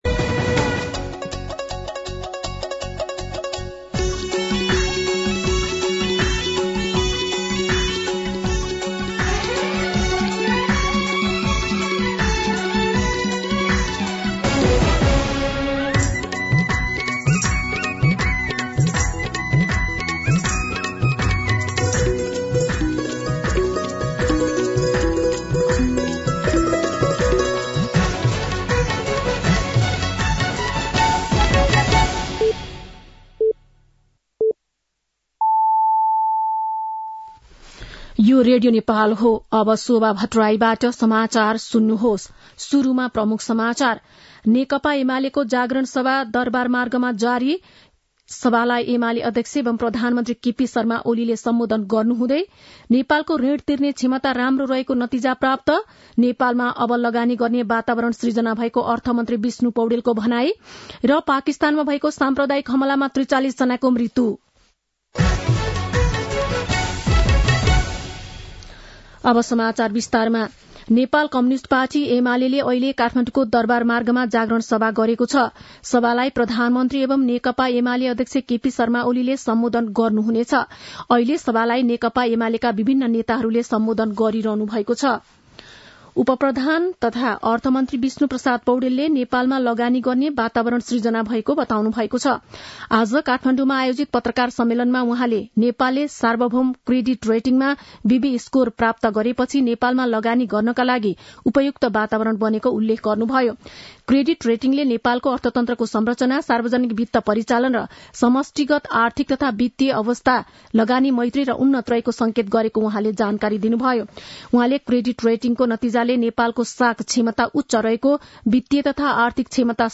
दिउँसो ३ बजेको नेपाली समाचार : ८ मंसिर , २०८१
3-pm-nepali-news-1-6.mp3